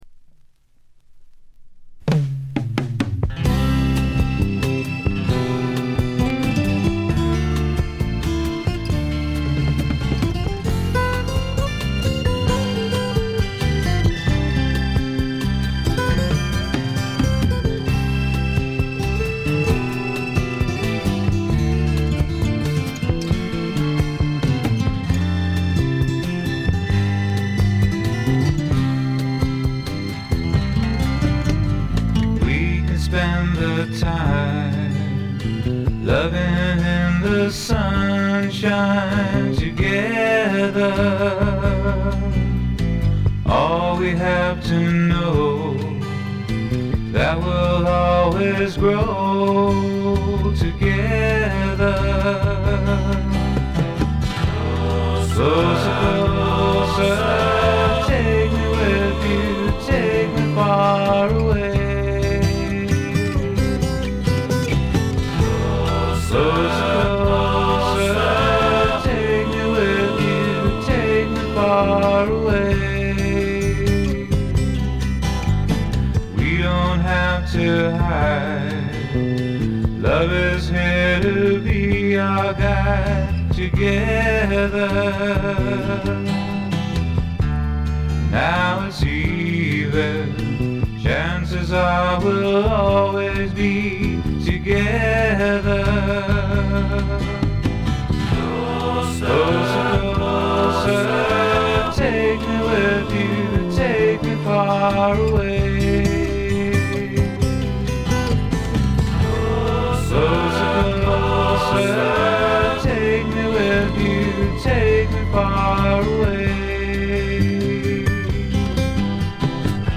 部分試聴ですがわずかなノイズ感のみ。
潮風に乗せたちょっとフォーキーなAORといったおもむきが心地よいです。
試聴曲は現品からの取り込み音源です。